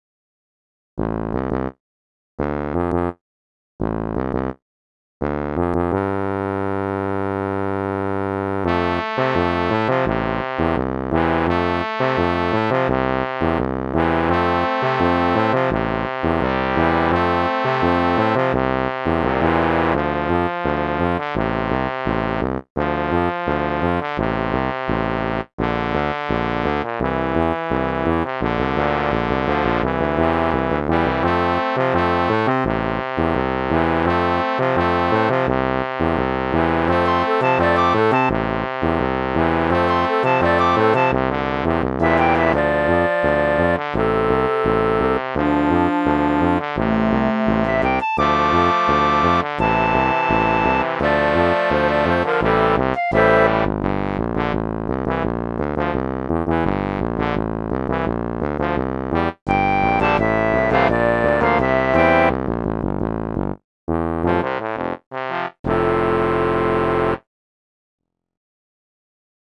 short piece for tuba, trombones, and piccolo